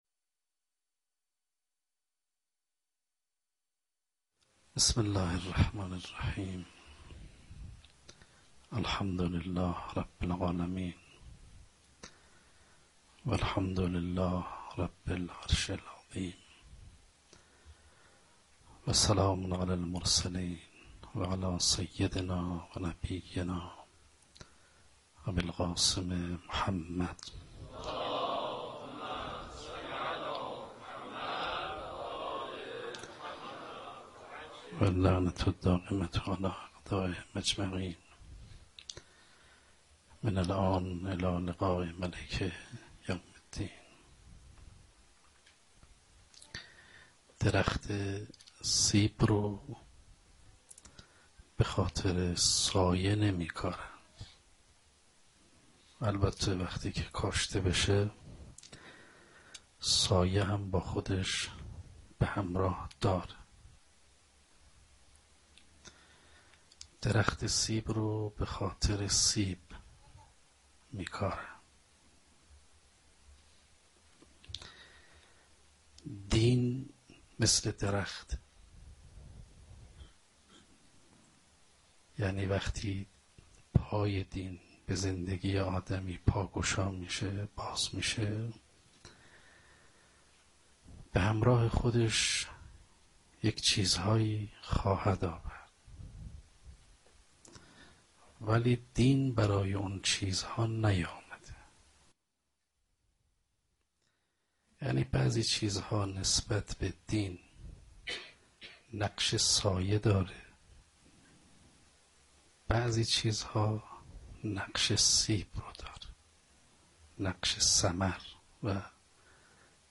شب دوم محرم 95 - سخنرانی - دنیا و استفاده انسان از آن